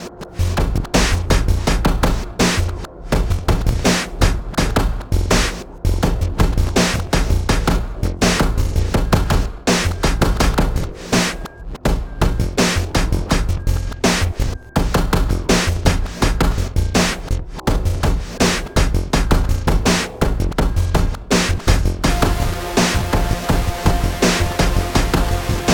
instant skinny= distorted 909... just did a quick clip in aum.. bs16 909 sf2 and mixed in DM2